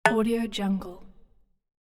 دانلود افکت صوتی ضربه زدن
تراک صوتی  Short Stab Denial Interface یک گزینه عالی برای هر پروژه ای است که به صداهای رابط و جنبه های دیگر مانند رابط، دکمه و برنامه نیاز دارد.
Sample rate 16-Bit Stereo, 44.1 kHz
Looped No